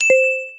dong.mp3